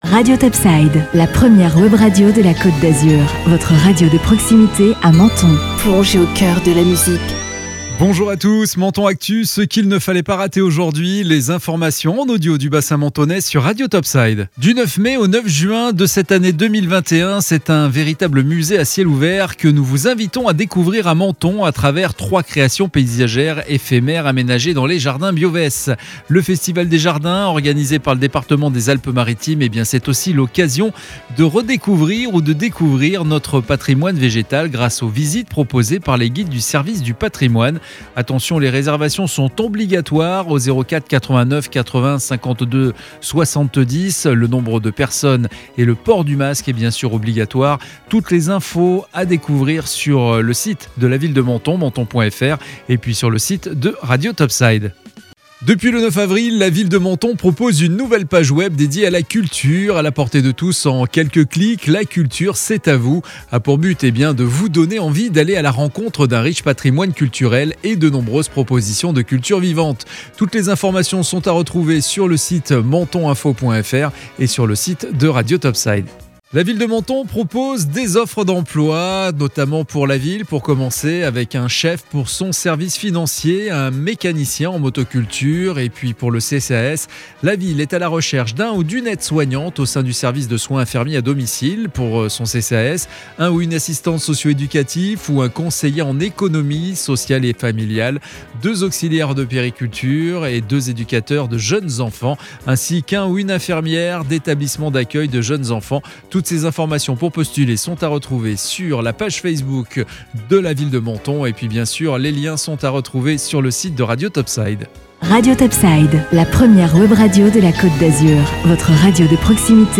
Menton Actu - Le flash info du mardi 27 avril 2021